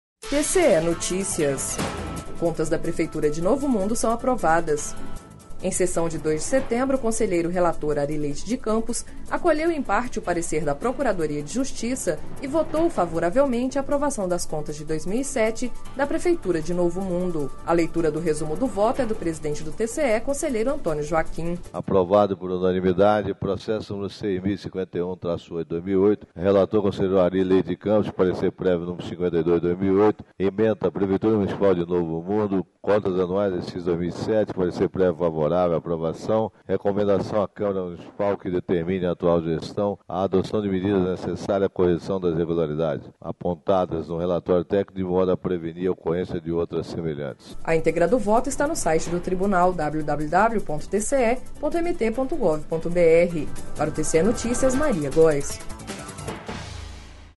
Em sessão de 02 de setembro, o conselheiro relator Ary Leite de Campos acolheu em parte o parecer da Procuradoria de Justiça e votou favoravelmente à aprovação das contas de 2007, da prefeitura de Novo Mundo./ A leitura do resumo do voto é do presidente do TCE, Antonio Joaquim.//
Sonora: Antonio Joaquim – conselheiro presidente do TCE